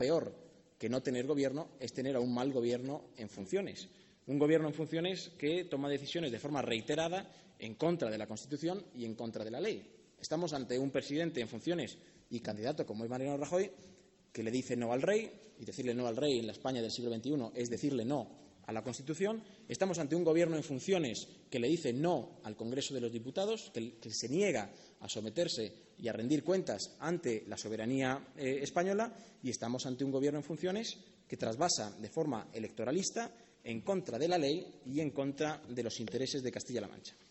corte3._portavoz._trasvase.mp3